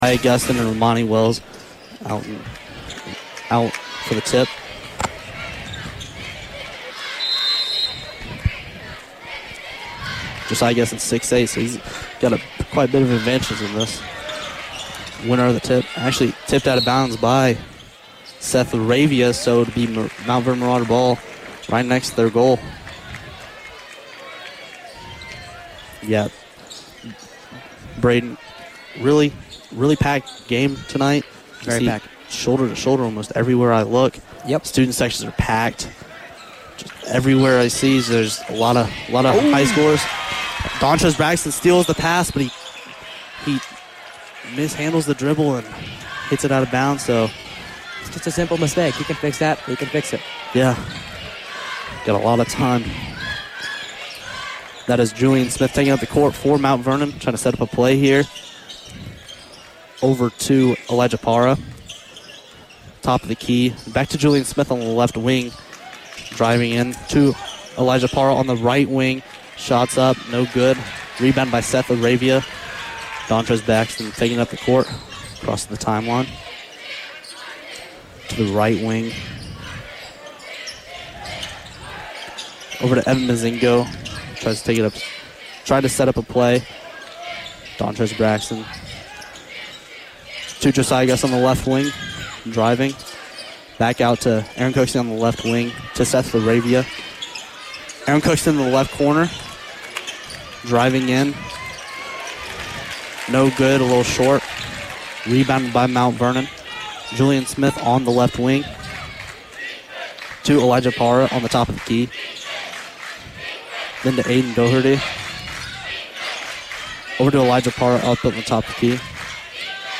Varsity Boys Basketball Broadcast Replay Pendleton Heights vs. Mount Vernon 12-8-23